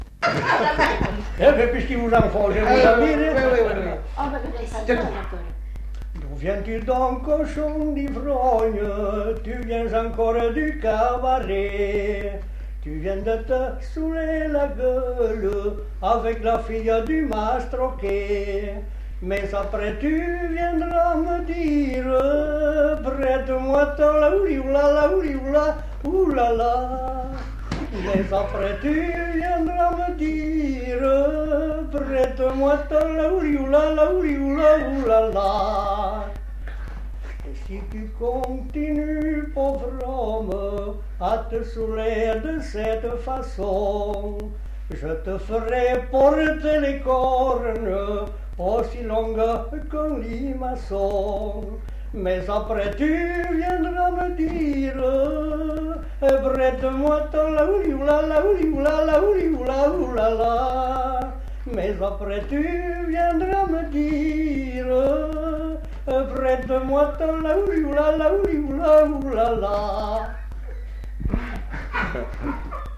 Aire culturelle : Agenais
Genre : chant
Effectif : 1
Type de voix : voix d'homme
Production du son : chanté
Description de l'item : fragment ; 2 c. ; refr.